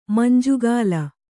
♪ manjugāla